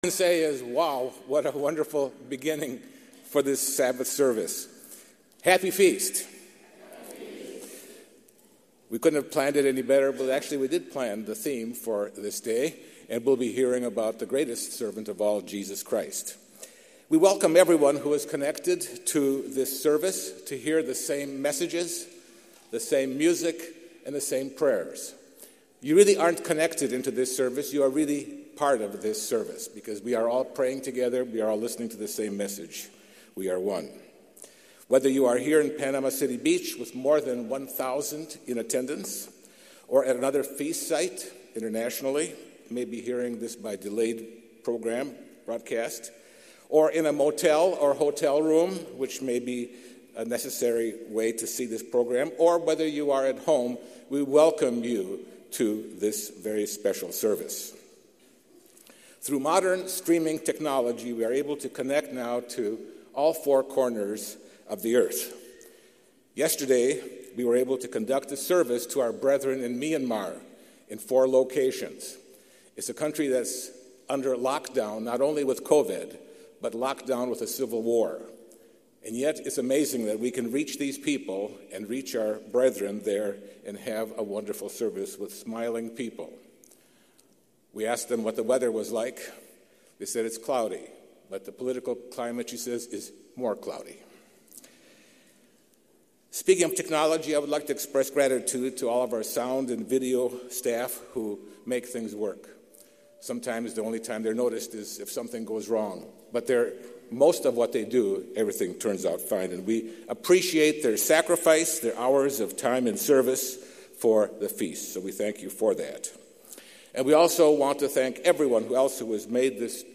This sermon was given at the Panama City Beach, Florida 2021 Feast site.